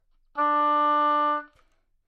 双簧管单音 " 双簧管 D4
描述：在巴塞罗那Universitat Pompeu Fabra音乐技术集团的goodsounds.org项目的背景下录制。
Tag: 好声音 D4 单注 多重采样 纽曼-U87 双簧管